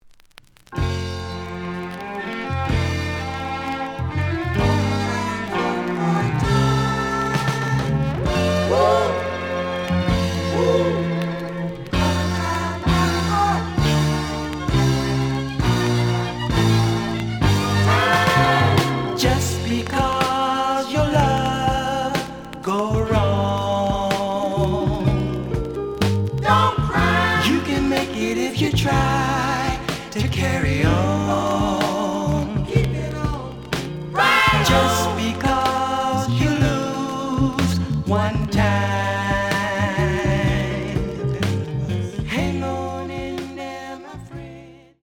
試聴は実際のレコードから録音しています。
●Format: 7 inch
●Genre: Funk, 70's Funk